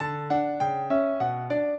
piano
minuet13-10.wav